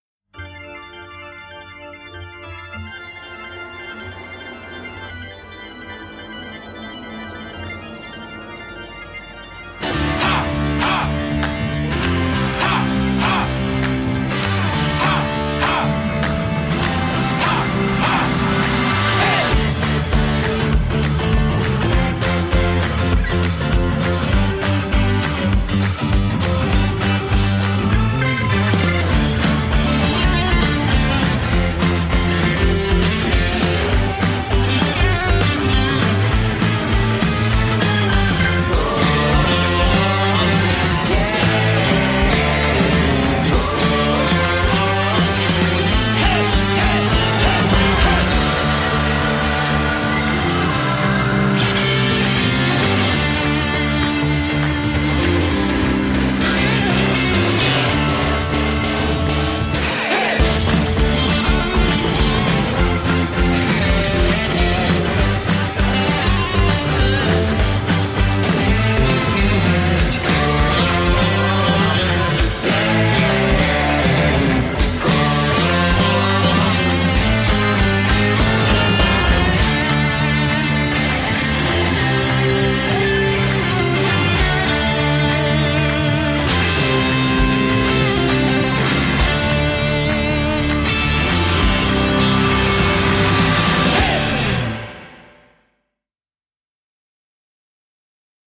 Pastiche de western spaghetti avec guitare électrique